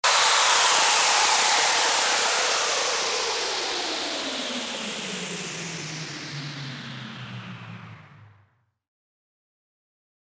FX-1835-WHOOSH
FX-1835-WHOOSH.mp3